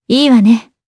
Ripine-Vox_Happy5_jp.wav